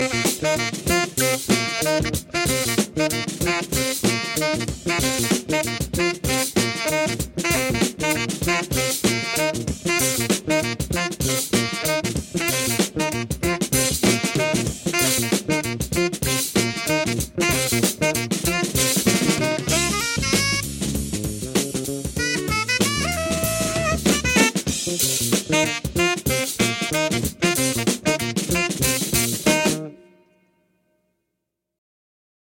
funk 7" vinyl